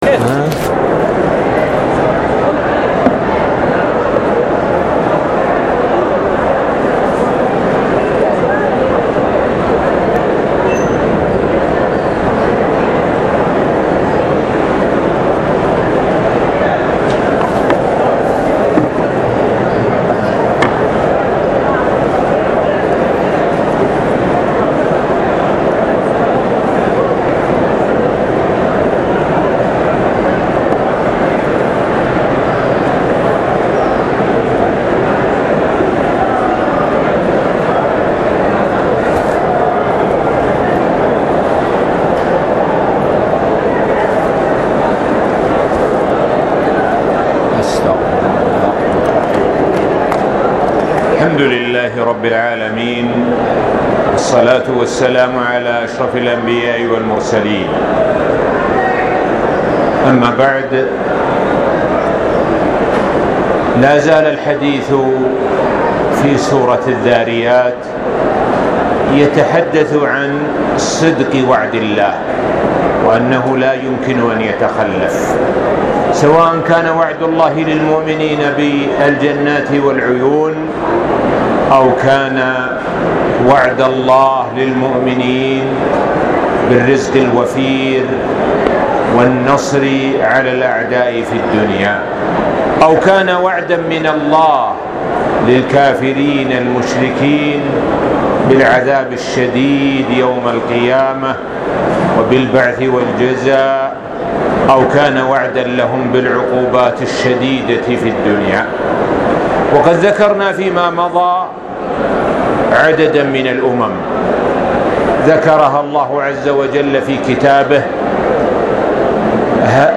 الموقع الرسمي لفضيلة الشيخ الدكتور سعد بن ناصر الشثرى | الدرس الرابع : سورة الذاريات (41-51)